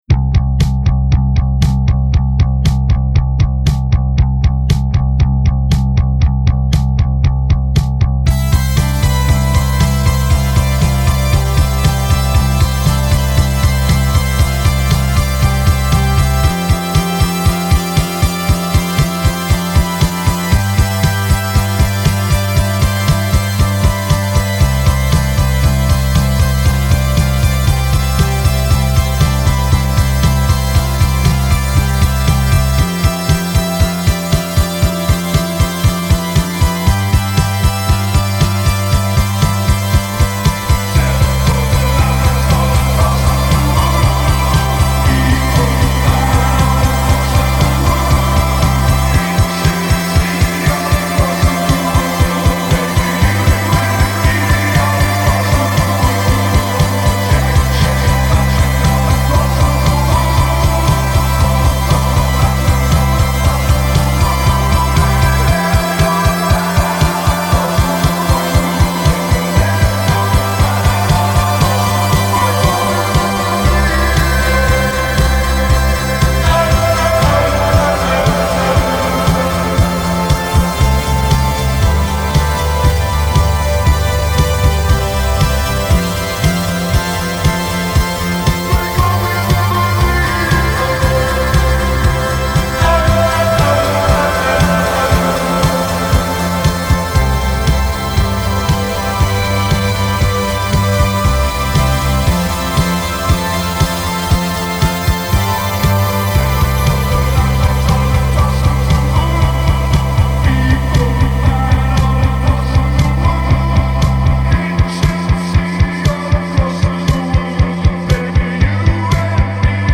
New Wave/Post Punk
heavy on synths
heavily reverbed vocals have a flat, monotone style
as well as those of a female backup.